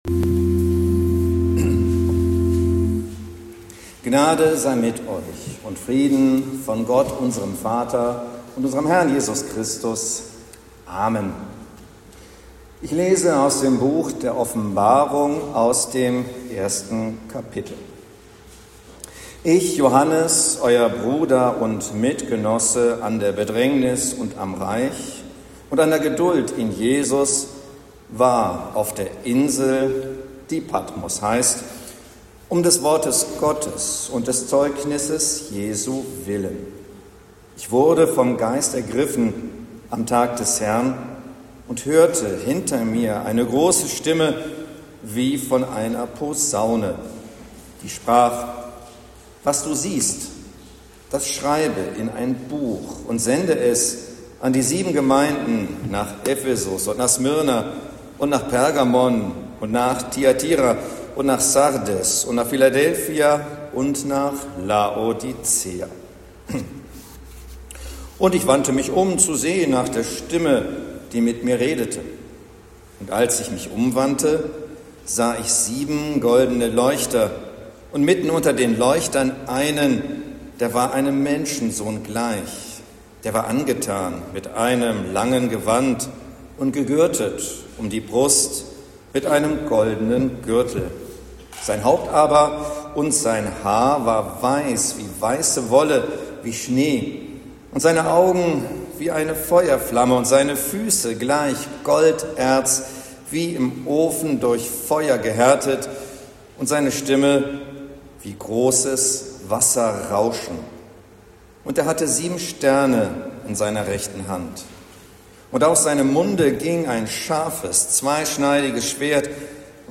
Predigt zu Sexagesimä